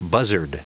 Prononciation du mot buzzard en anglais (fichier audio)
Prononciation du mot : buzzard